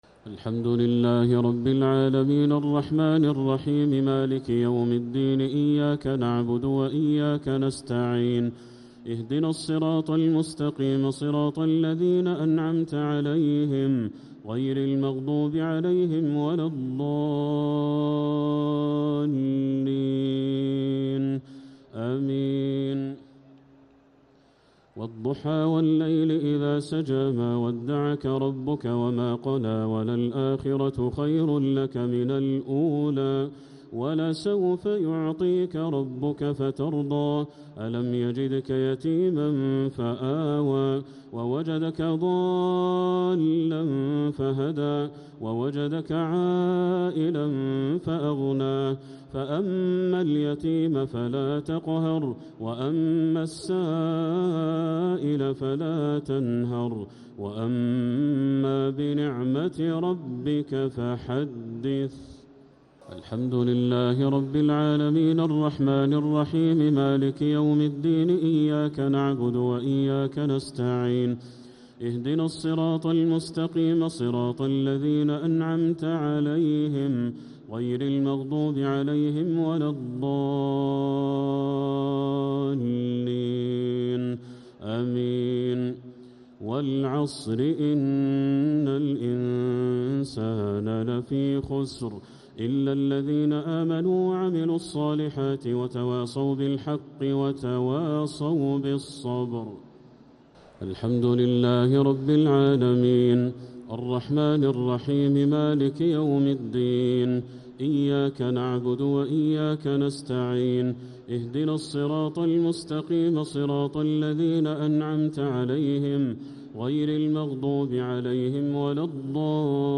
الشفع و الوتر ليلة 23 رمضان 1447هـ | Witr 23rd night Ramadan 1447H > تراويح الحرم المكي عام 1447 🕋 > التراويح - تلاوات الحرمين